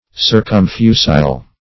Search Result for " circumfusile" : The Collaborative International Dictionary of English v.0.48: Circumfusile \Cir`cum*fu"sile\, a. [Pref. circum- + L. fusilis fusil, a.] Capable of being poured or spread round.
circumfusile.mp3